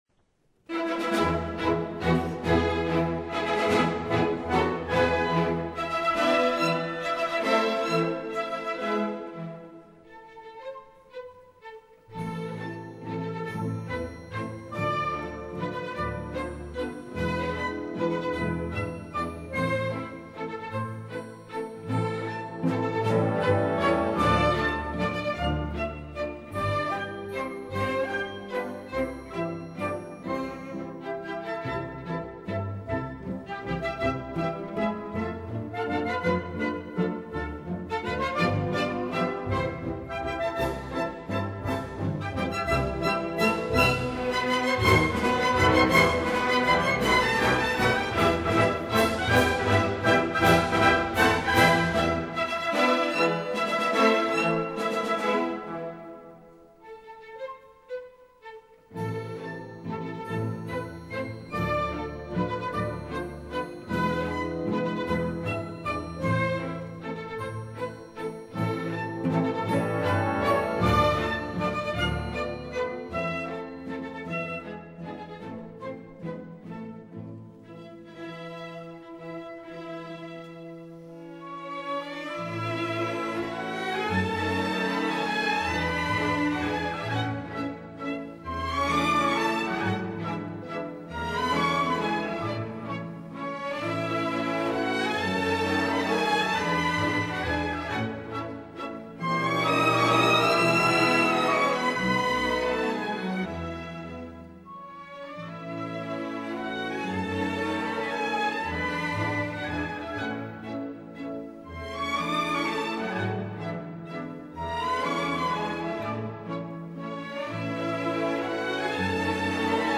Polka mazur